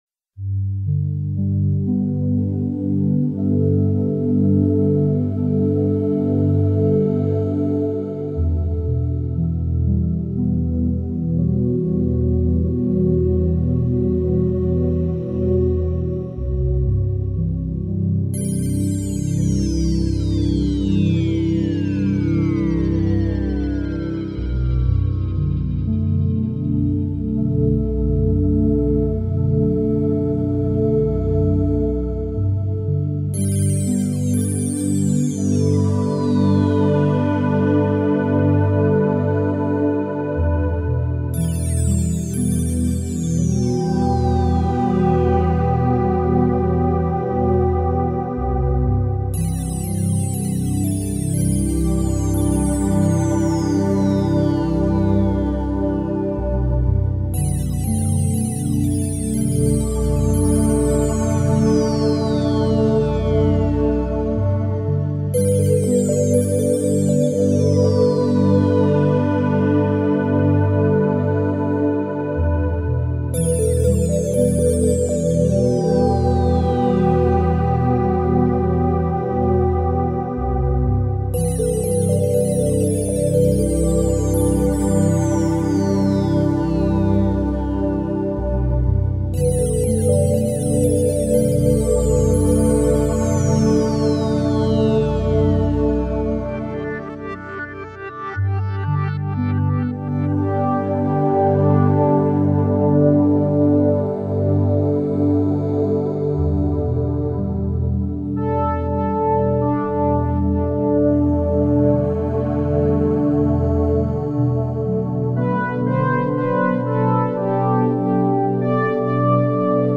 2020 CHANTS REIKI audio closed https